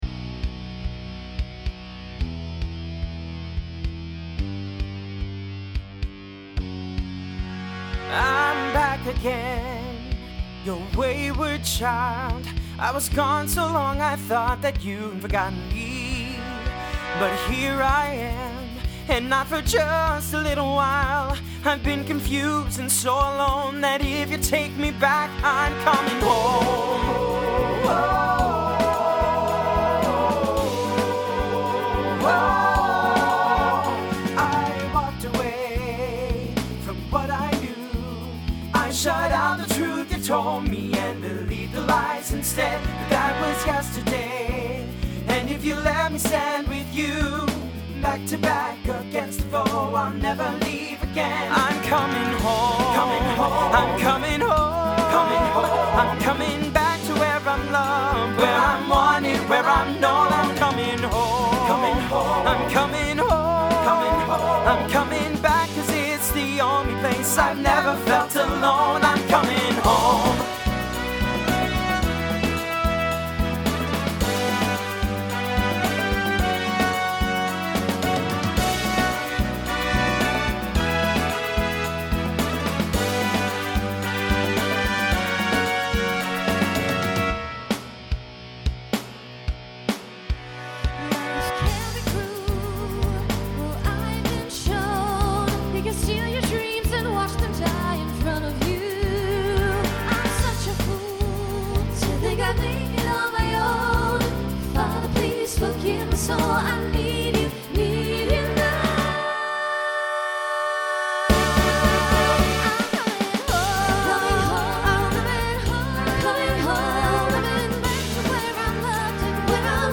TTB/SSA
Voicing Mixed Instrumental combo Genre Country , Pop/Dance